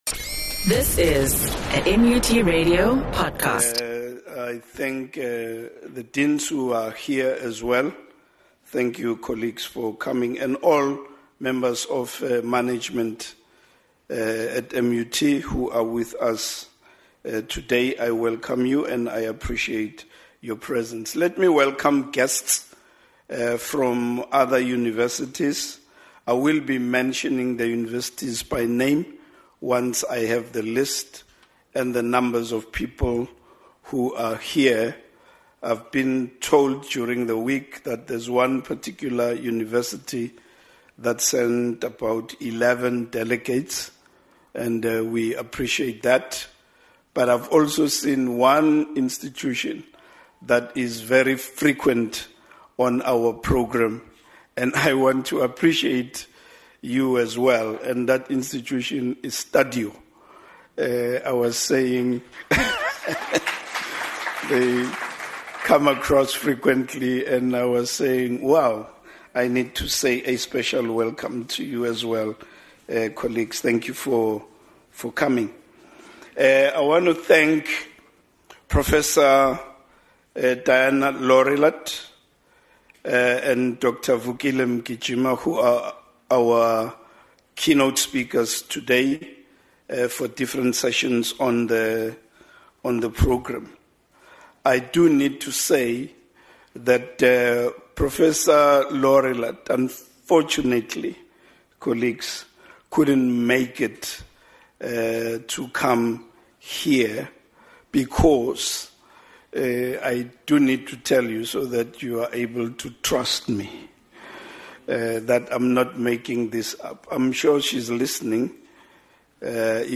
The Teaching and Learning Directorate hosts the 2026 Teaching with Technology Summit, with various speakers covering different topics.